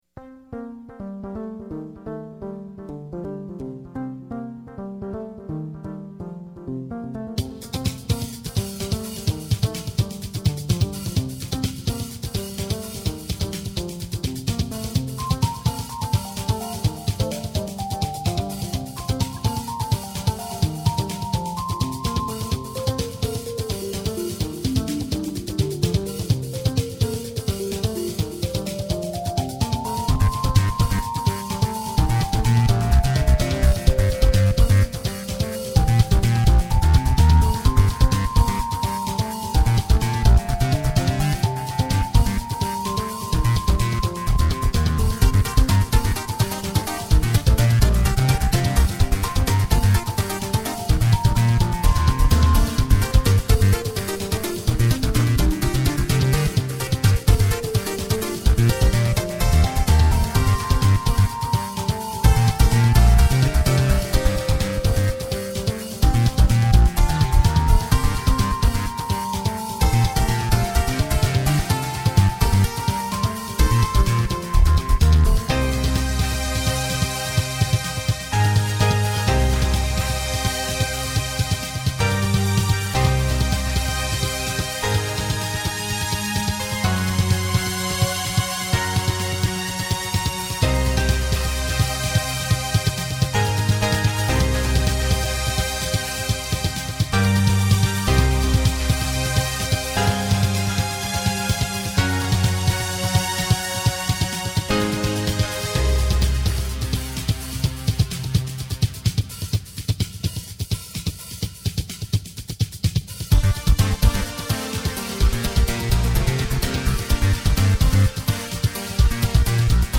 Punk/Rock Pieces